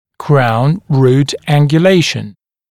[kraun ruːt ˌæŋgju’leɪʃn][краун ру:т ˌэнгйу’лэйшн]коронково-корневой наклон